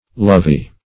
Search Result for " lovee" : The Collaborative International Dictionary of English v.0.48: Lovee \Lov*ee"\, n. One who is loved.